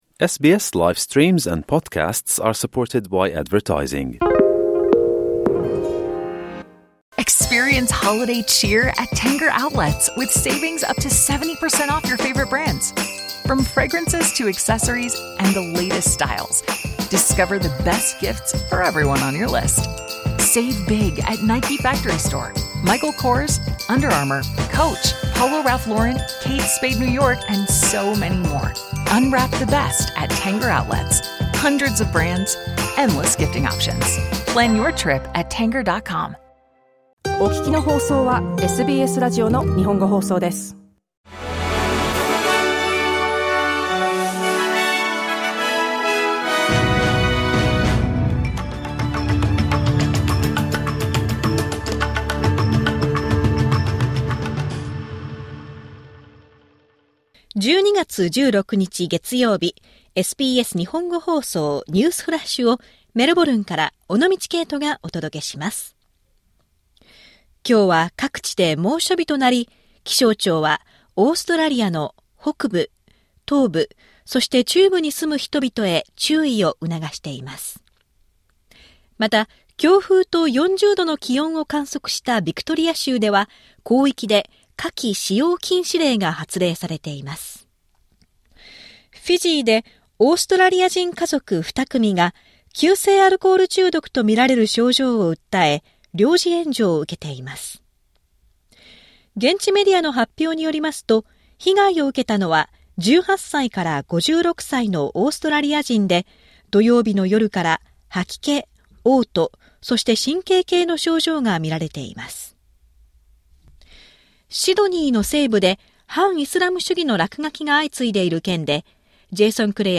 SBS Japanese Newsflash Monday 16 December